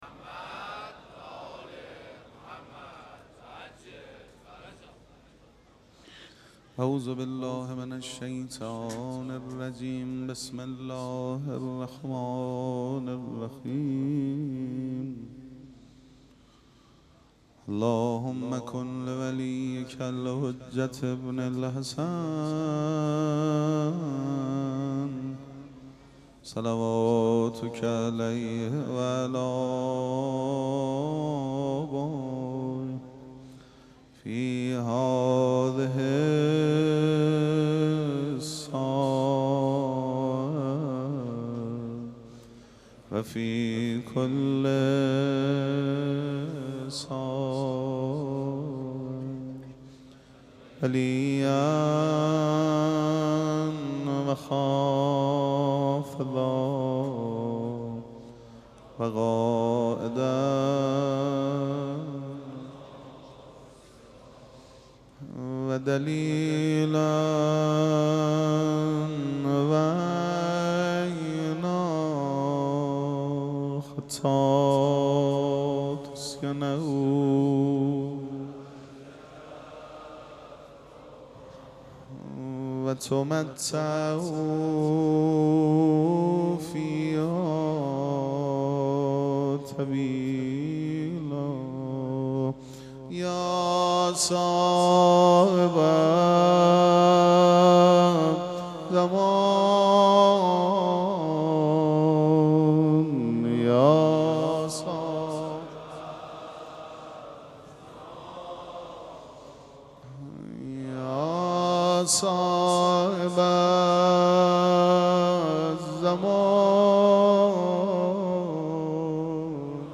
مراسم عزاداری شب دهم محرم الحرام ۱۴۴۷
پیش منبر